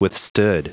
Prononciation du mot withstood en anglais (fichier audio)
Prononciation du mot : withstood